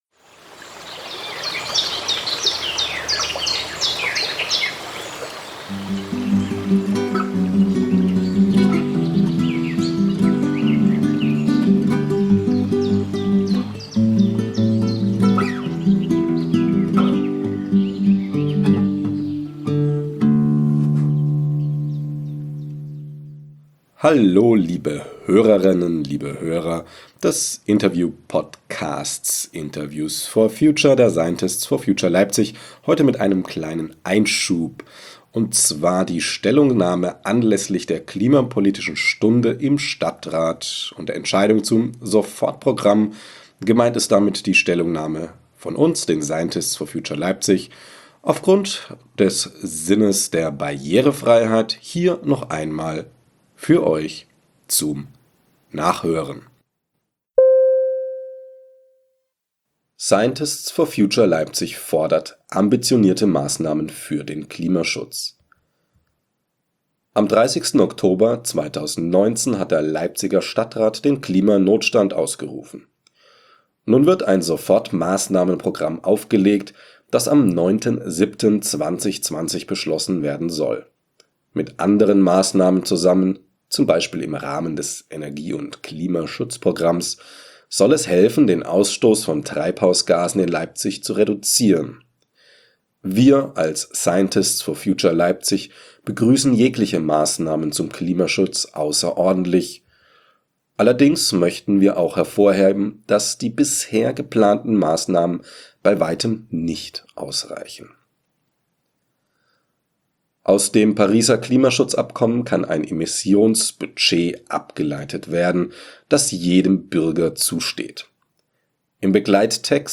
Stellungnahme anlässlich der klimapolitischen Stunde im Stadtrat und der Entscheidung zum Sofortprogramm // Klimapodcast
- unverändert (der Rahmen ist durch die Musik gesetzt)